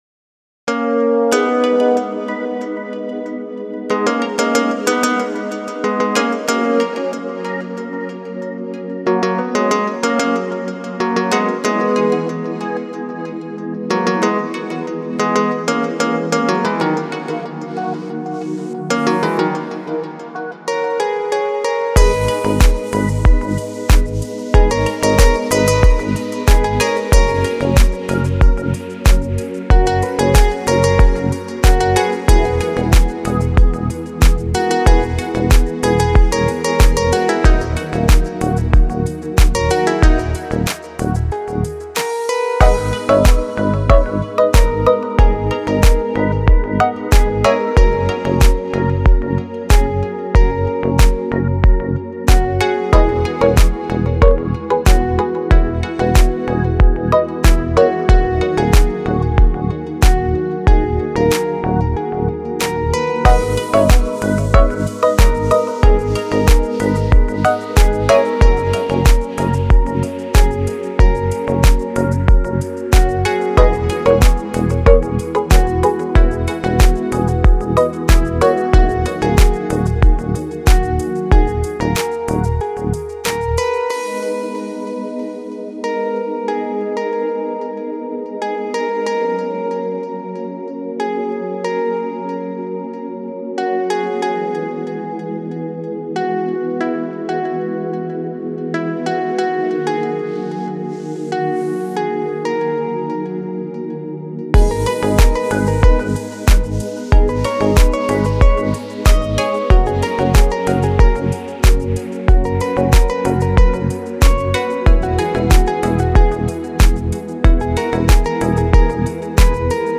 دیپ هاوس ریتمیک آرام موسیقی بی کلام